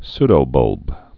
(sdō-bŭlb)